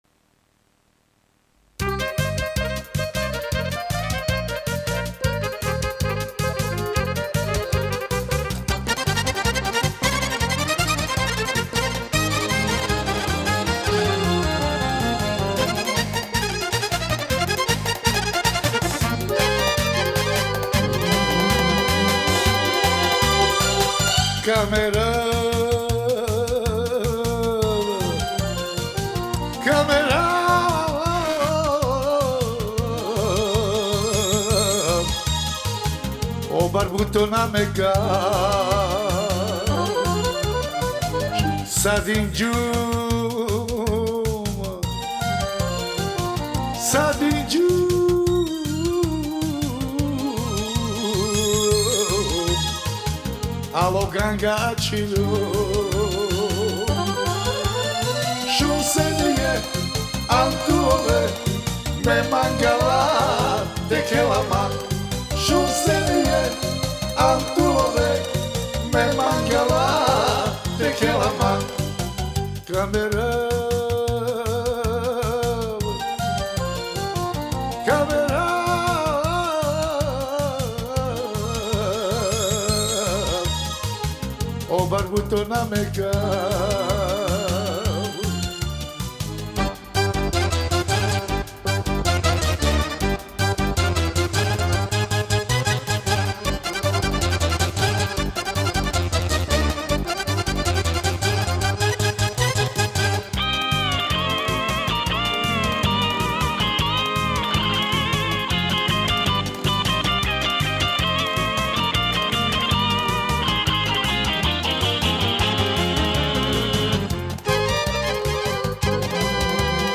Genre: Gypsy Jazz, World, Ethnic, Folk Balkan